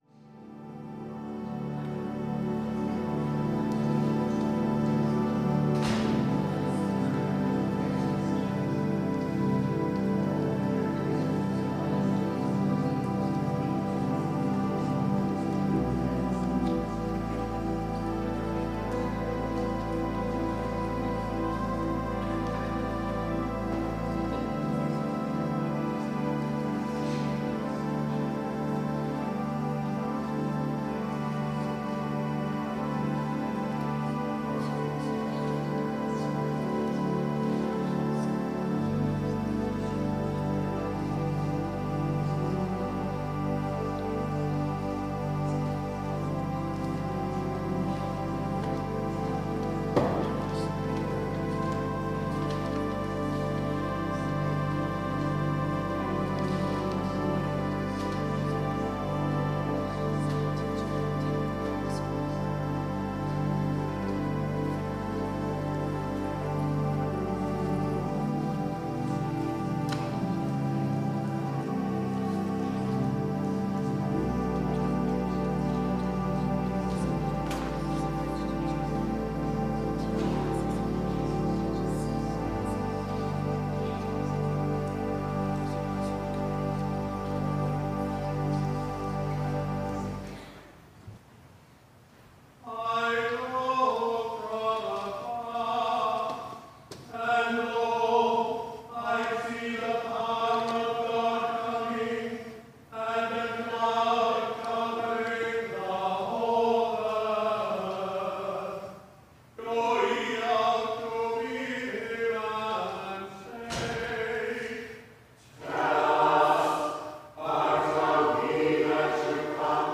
Worship and Sermon audio podcasts
Podcast from Christ Church Cathedral Fredericton
WORSHIP - 4:00 p.m. Advent Readings and Music